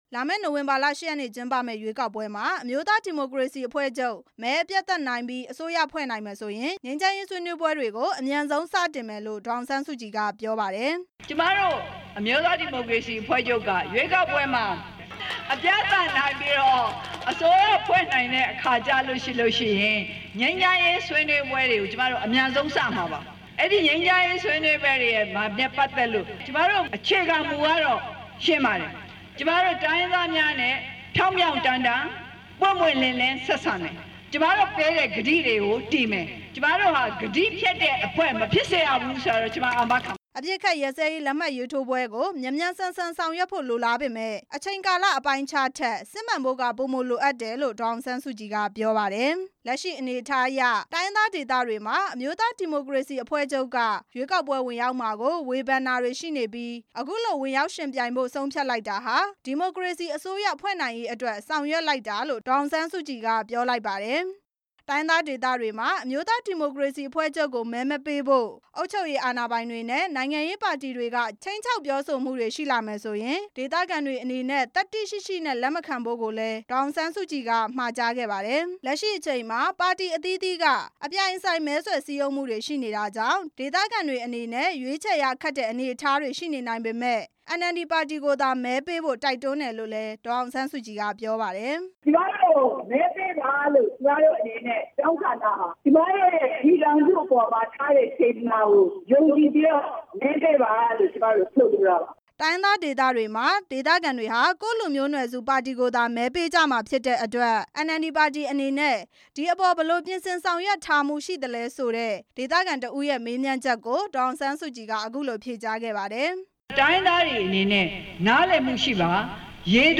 ဒီကနေ့ ရှမ်းပြည်တောင်ပိုင်း ဆီဆိုင်မြို့က မင်္ဂလာဘုရားဝင်းမှာ ဒေသခံလူထုတွေနဲ့တွေ့ဆုံပြီး ရွေးကောက်ပွဲွ အသိပညာပေးဟောပြောပွဲမှာ ဒေါ်အောင်ဆန်းစုကြည်က ပြောလိုက်တာဖြစ်ပါတယ်။